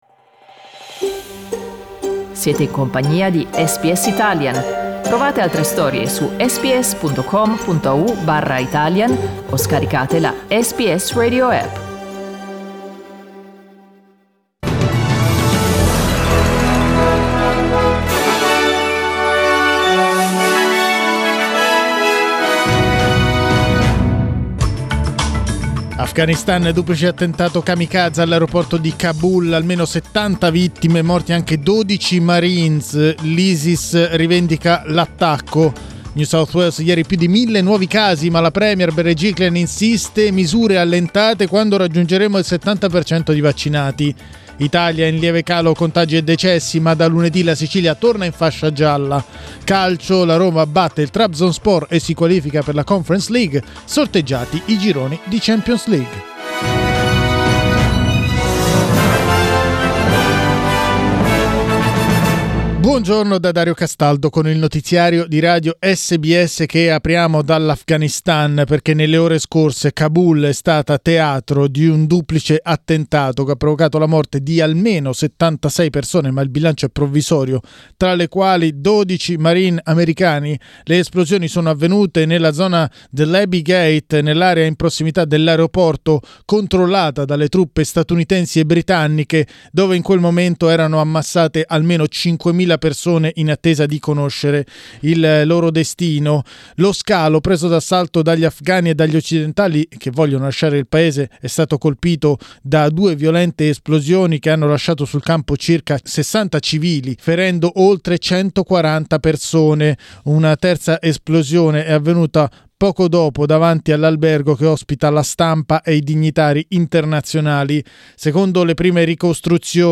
Giornale radio venerdì 28 agosto 2021
Il notiziario di SBS in italiano.